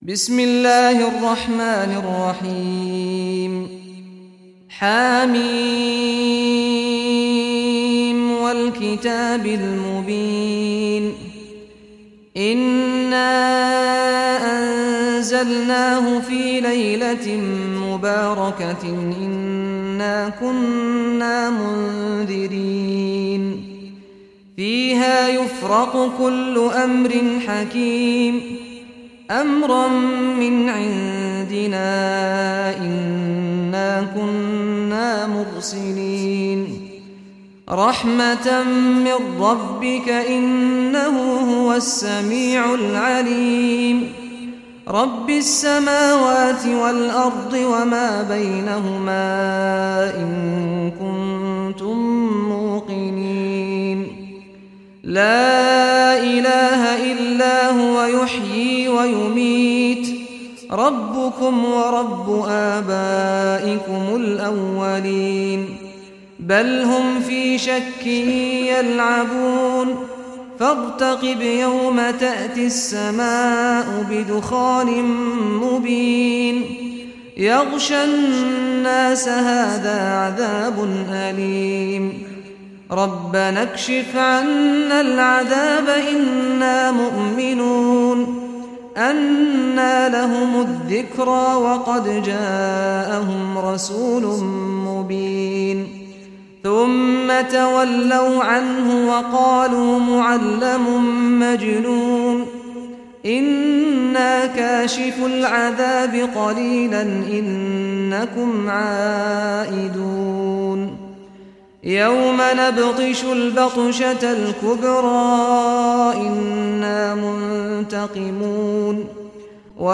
Surah Ad-Dukhan Recitation by Sheikh Saad Ghamdi
Surah Ad-Dukhan, listen or play online mp3 tilawat / recitation in Arabic in the voice of Sheikh Saad al Ghamdi.